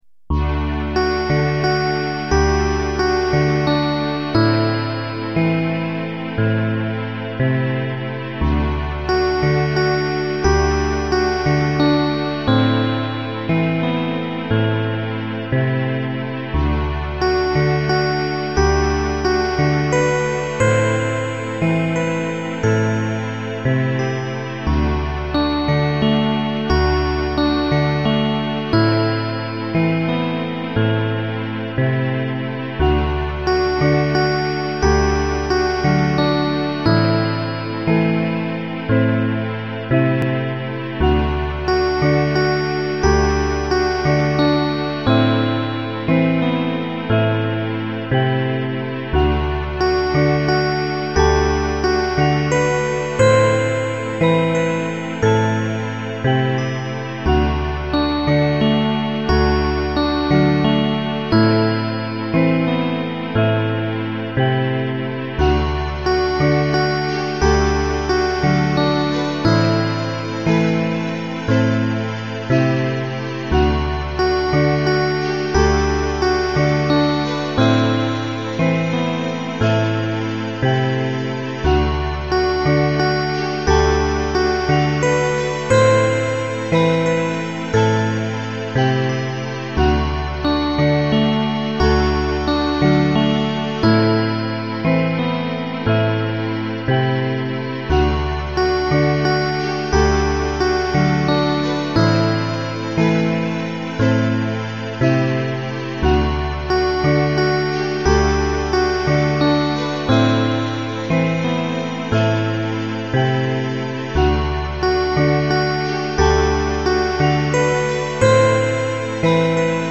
• Music has an ending (Doesn't loop)
• Chip music